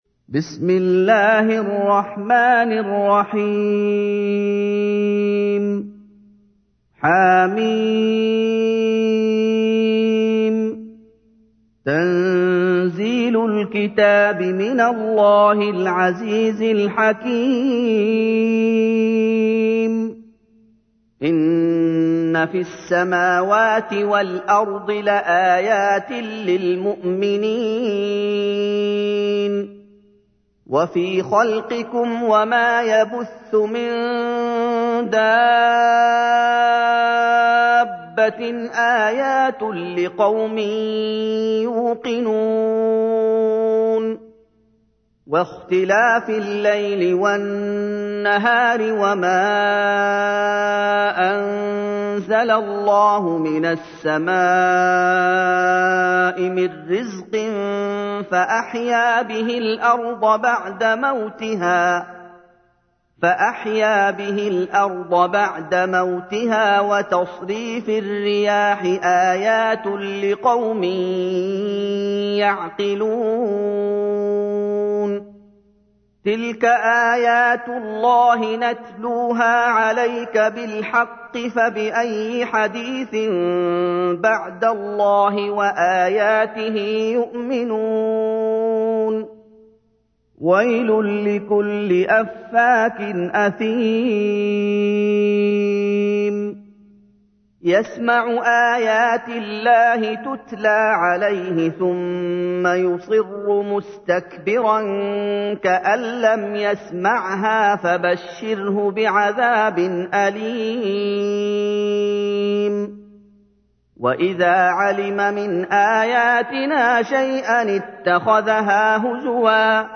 تحميل : 45. سورة الجاثية / القارئ محمد أيوب / القرآن الكريم / موقع يا حسين